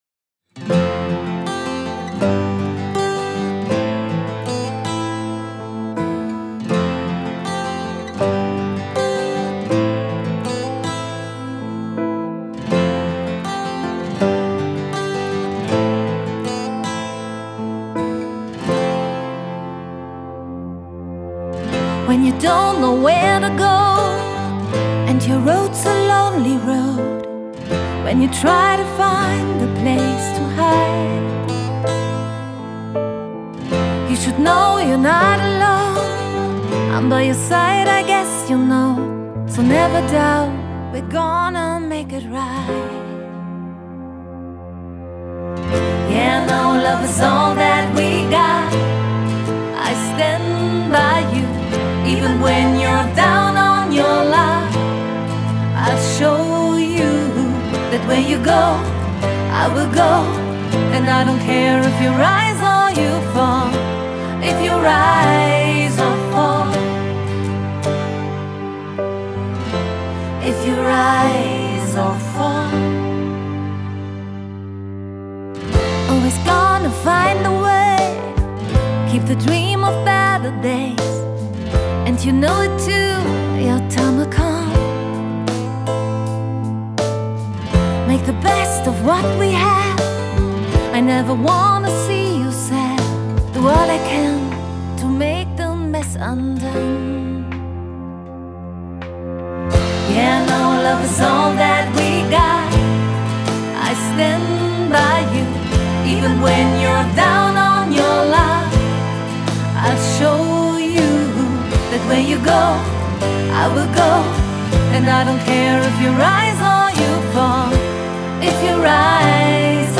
Vielseitige Sängerin mit großer Stimmgewalt und viel Gefühl macht Ihr Event unvergesslich!